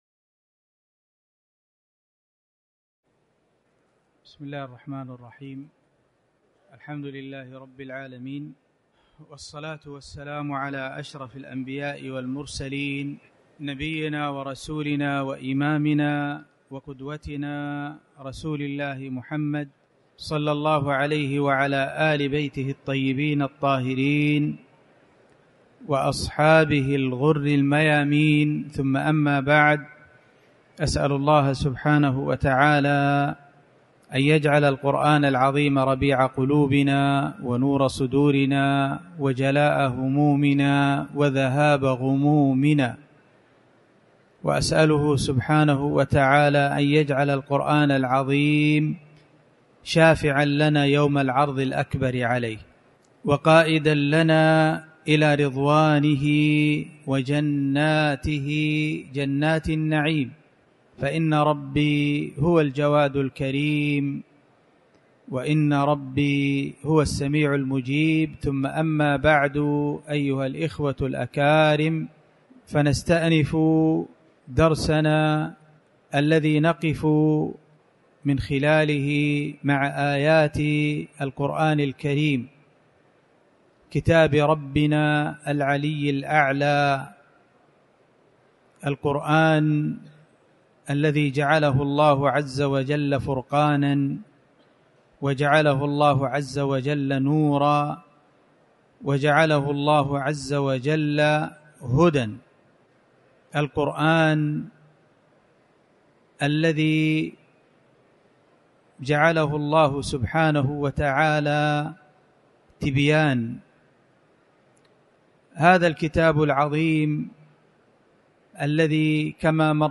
تاريخ النشر ١٤ شوال ١٤٤٠ هـ المكان: المسجد الحرام الشيخ